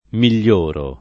miglioro [ mil’l’ 1 ro ]